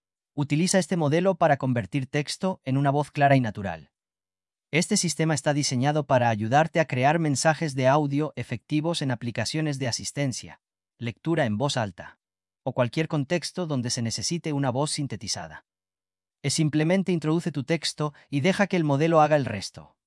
spanish text-to-speech voice-cloning
A F5-TTS fine-tuned for Spanish